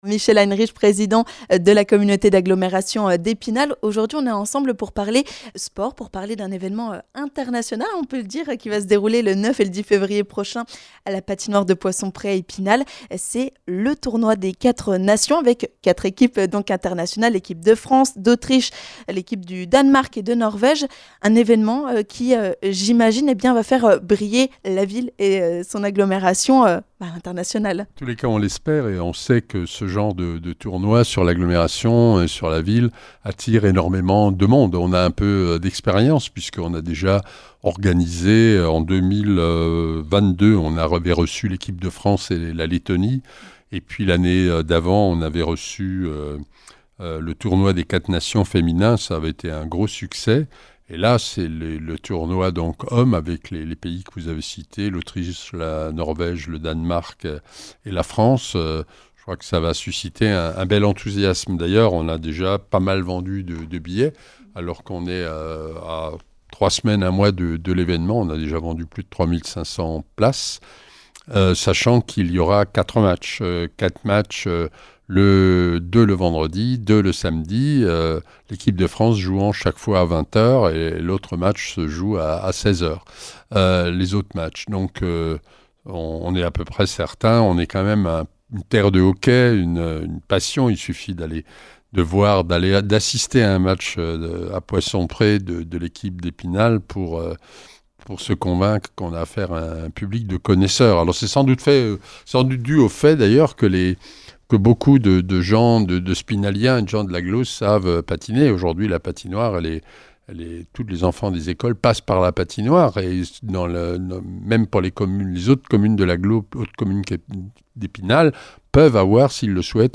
Le point avec Michel Heinrich, le président de la Communauté d'Agglomération d'Epinal.